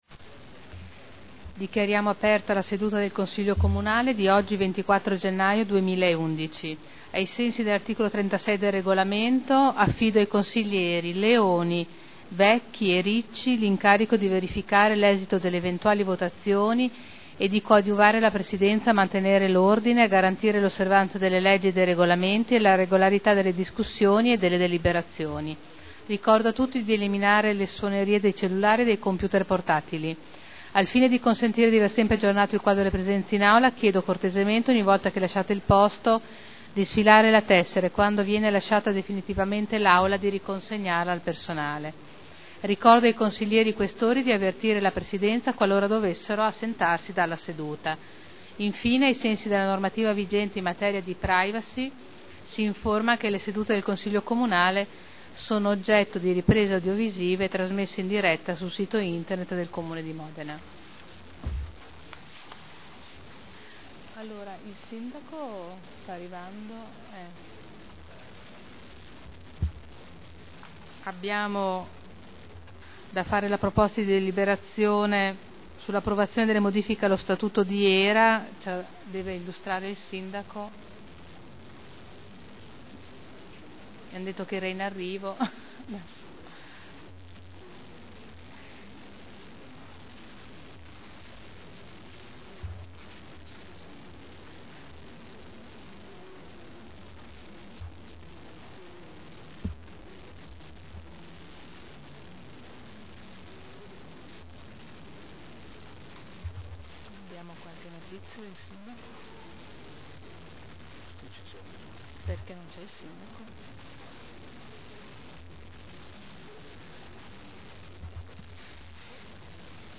Il Presidente Caterina Liotti apre i lavori del Consiglo Comunale.